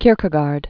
(kîrkĭ-gärd, -gôr), Søren Aaby 1813-1855.